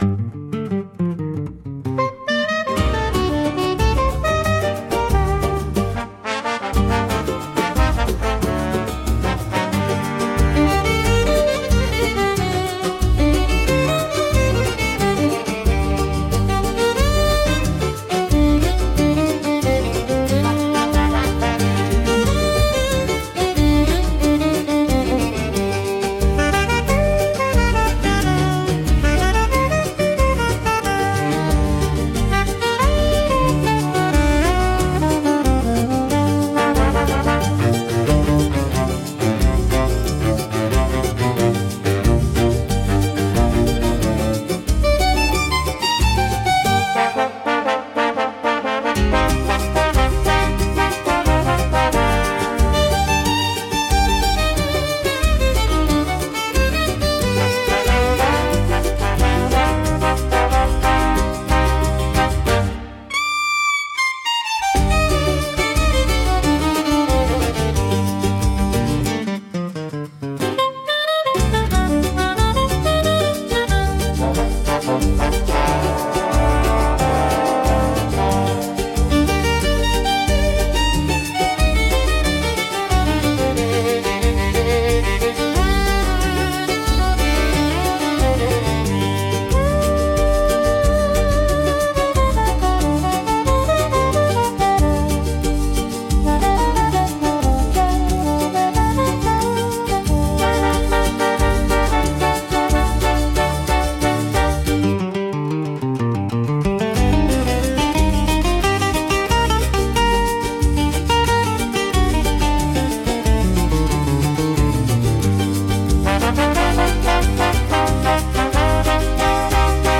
instrumental 3